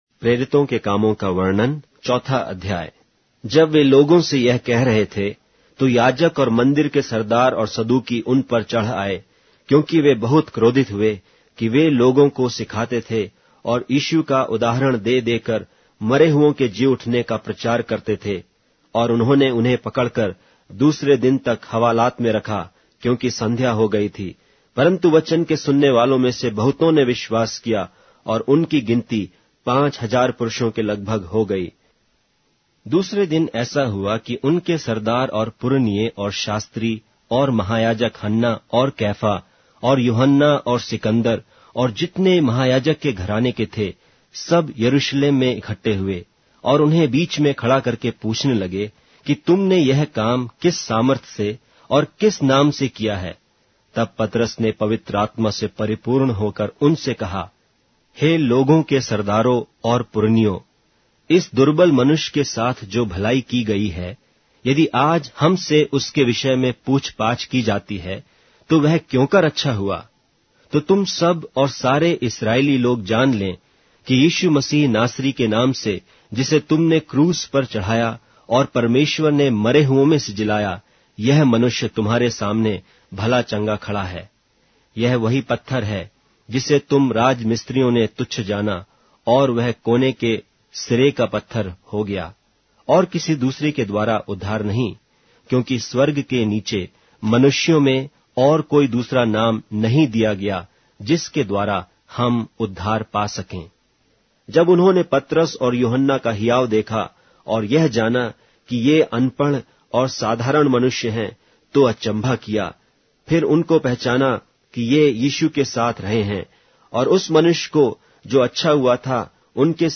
Hindi Audio Bible - Acts 14 in Nlt bible version